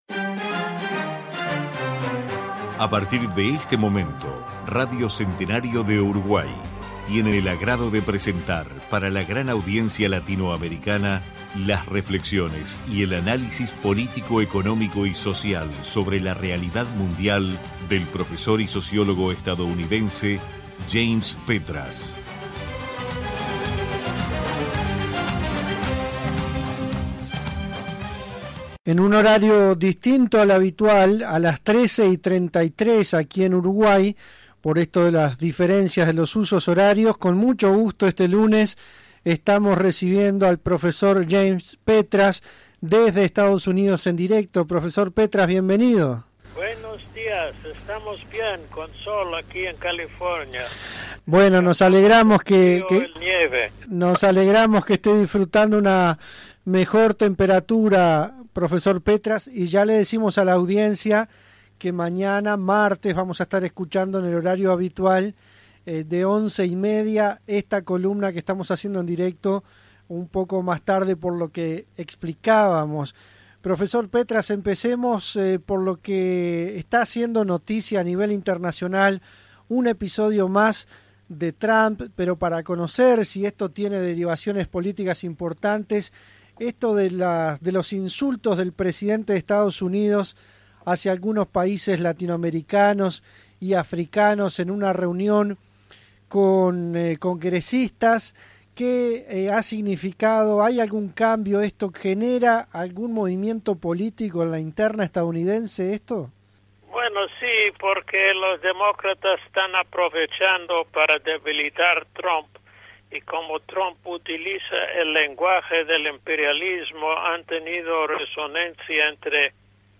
Columna semanal de opinión del sociólogo James Petras en Mañanas de Radio